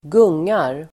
Uttal: [²g'ung:ar]